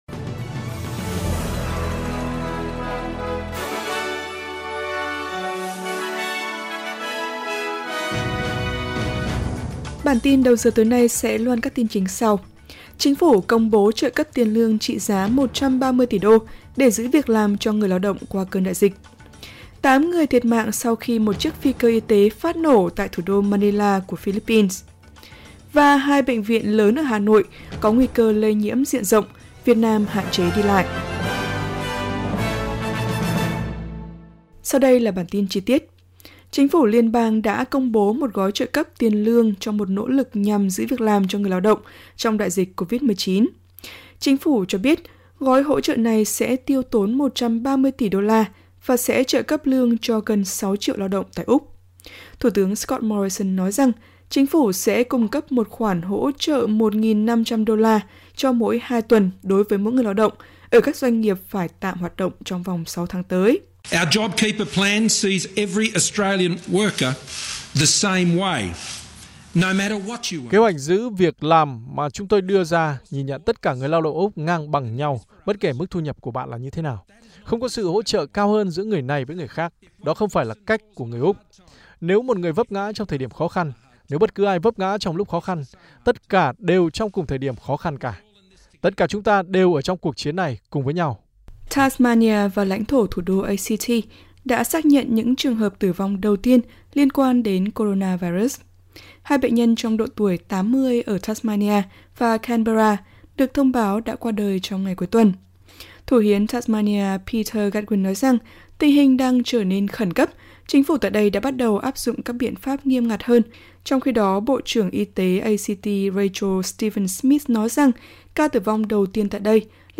vietnamese_news_gio_1_3003.mp3